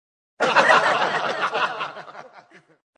Play, download and share Risas de publico original sound button!!!!
risas-de-publico.mp3